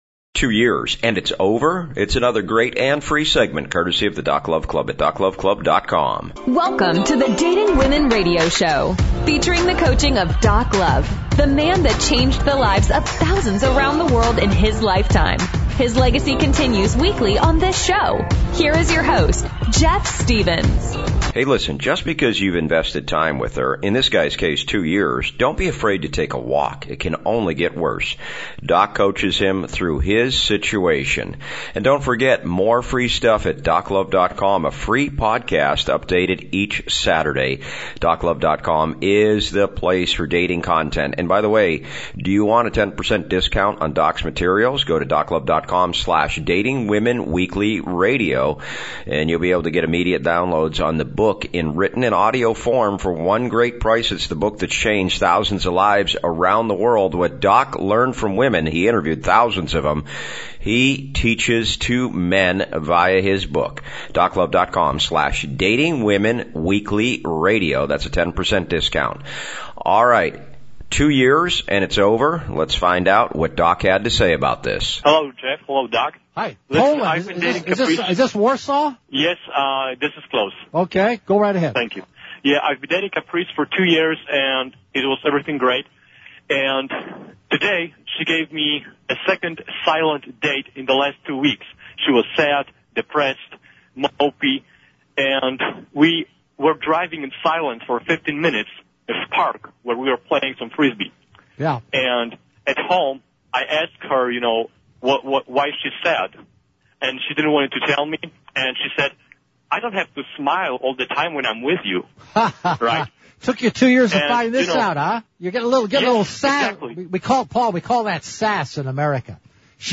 Dating Women Radio Show Excerpt: 2 Years And It’s Over???